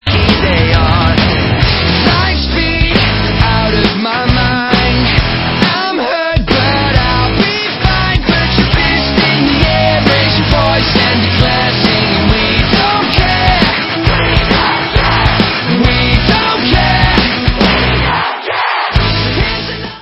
britští poprockeři